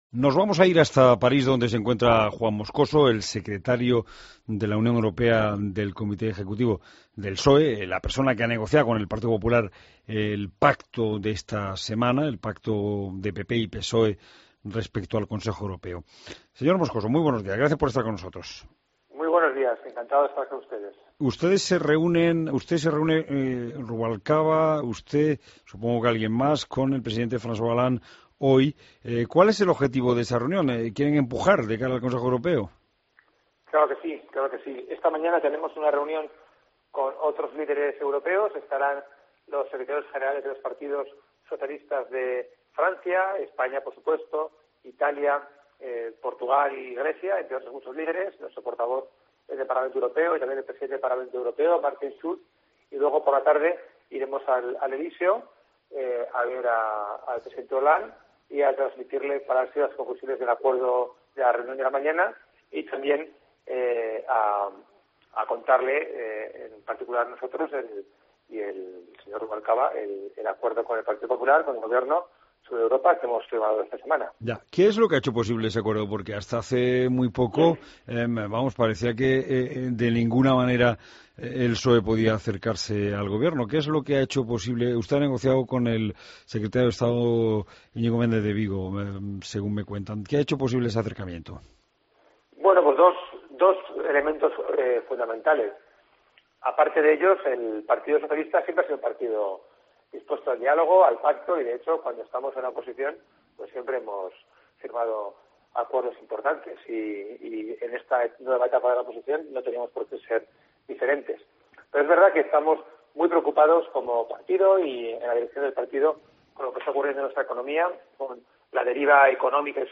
Entrevista a Juan Moscoso, secretario ejecutivo de la UE del PSOE